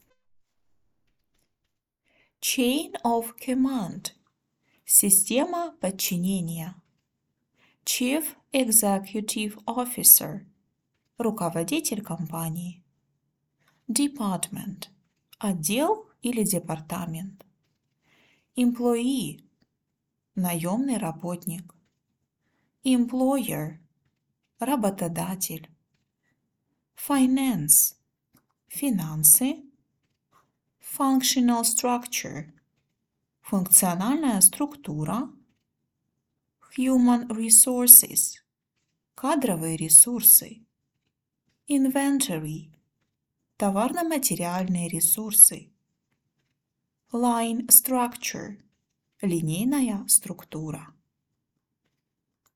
• прослушивайте озвученные английские фразы ежедневно, повторяйте за диктором сначала английский вариант, а затем и русский перевод;